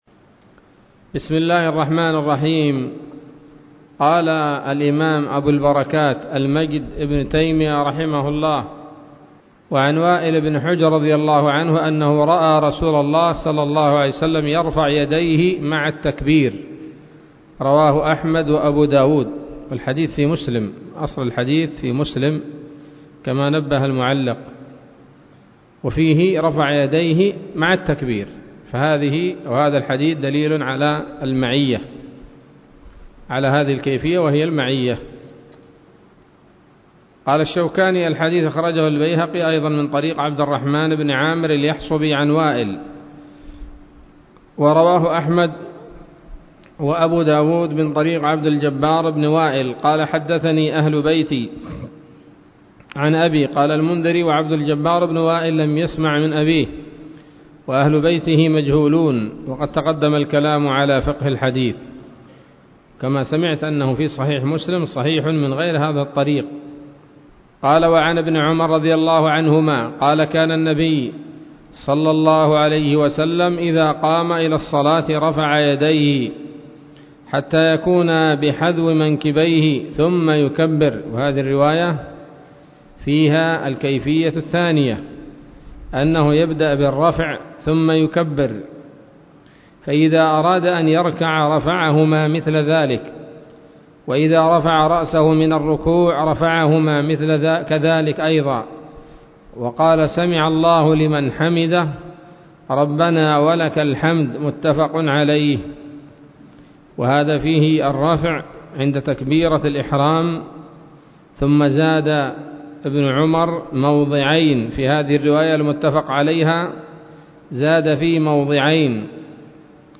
الدرس السادس من أبواب صفة الصلاة من نيل الأوطار